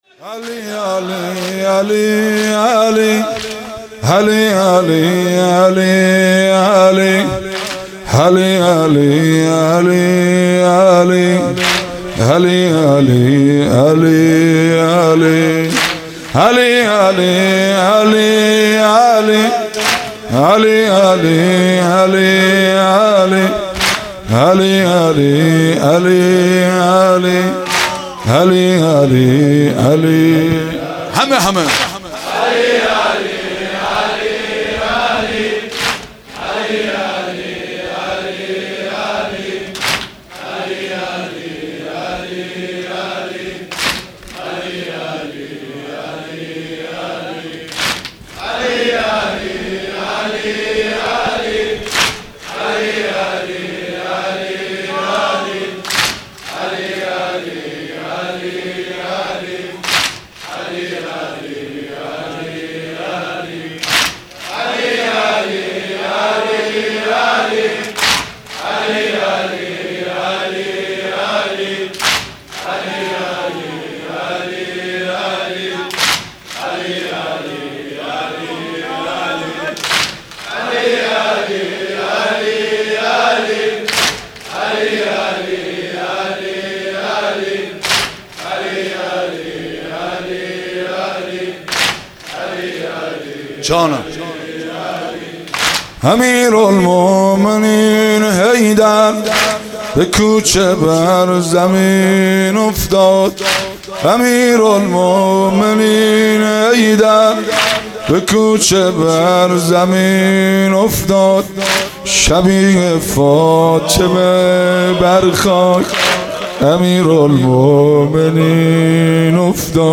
مناسبت : شب نوزدهم رمضان - شب قدر اول
قالب : زمینه